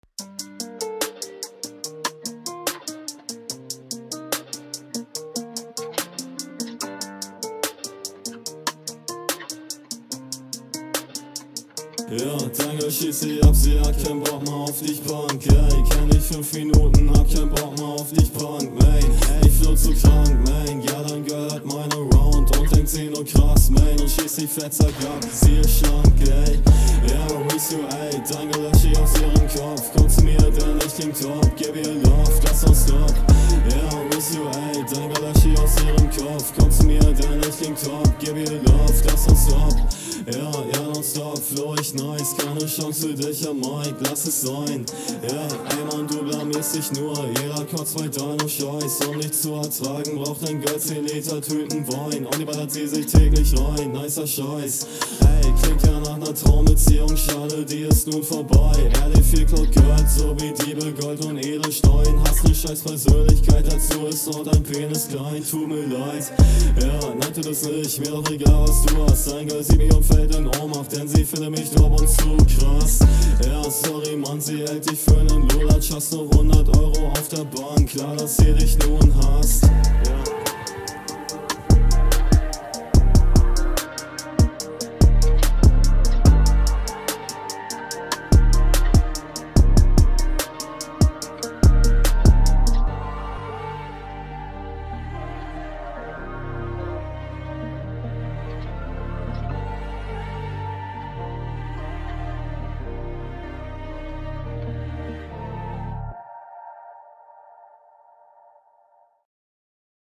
Flowpattern sind für die Train schon sehr advanced und alles scheint sehr sicher.
Chilliger Beat und misch technisch ist …